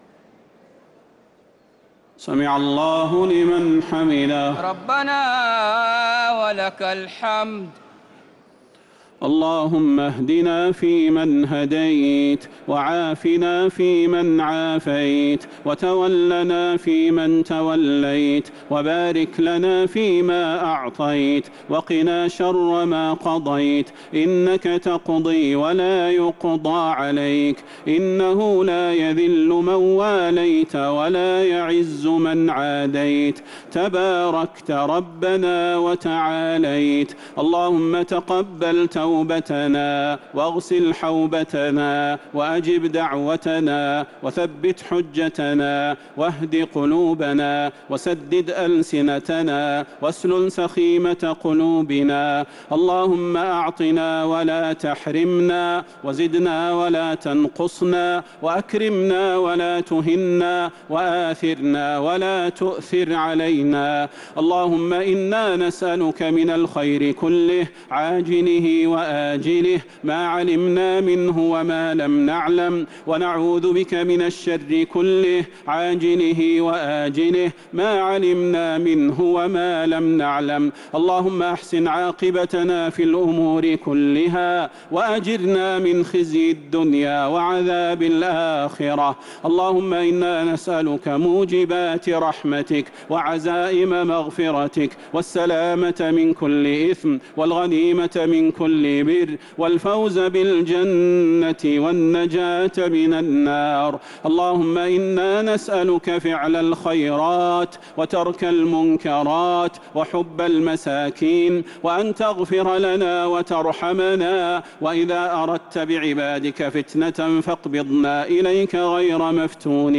دعاء القنوت ليلة 14 رمضان 1447هـ | Dua 14th night Ramadan 1447H > تراويح الحرم النبوي عام 1447 🕌 > التراويح - تلاوات الحرمين